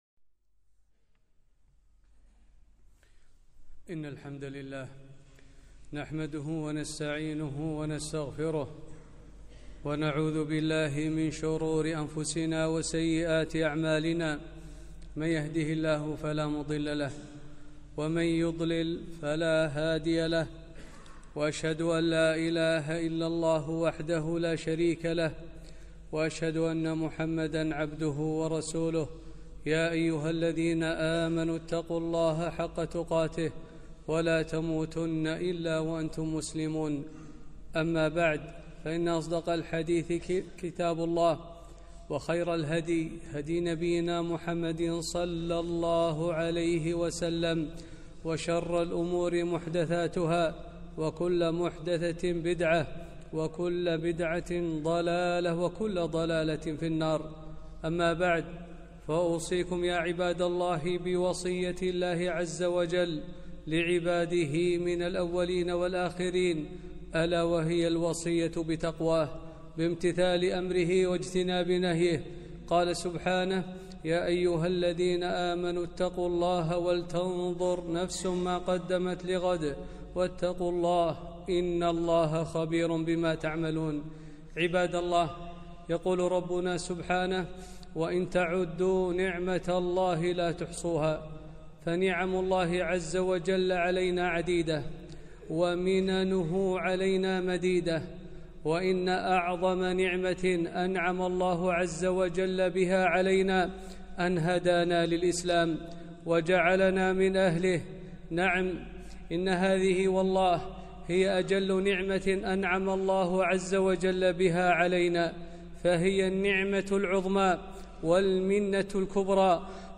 خطبة - الإسلام أعظم النعم